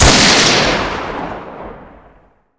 sol_reklam_link sag_reklam_link Warrock Oyun Dosyalar� Ana Sayfa > Sound > Weapons > Psg Dosya Ad� Boyutu Son D�zenleme ..
WR_fire.wav